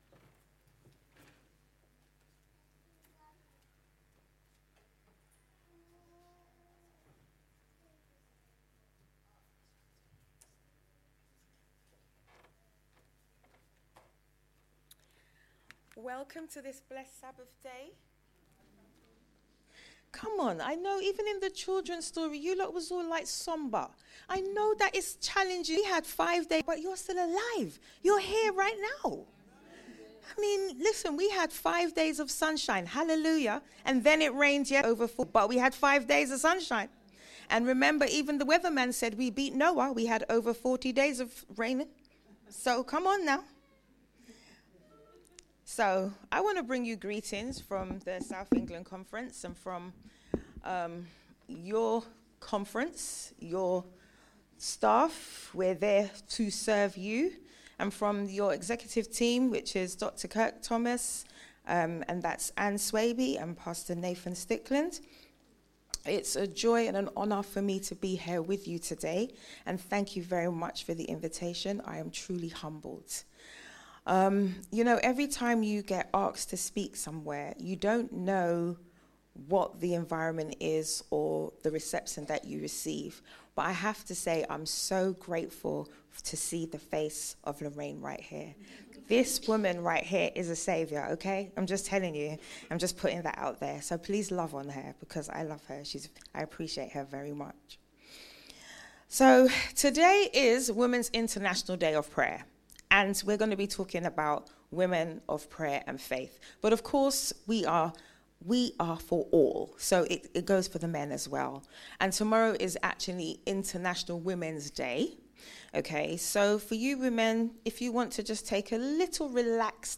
Family Service Recordings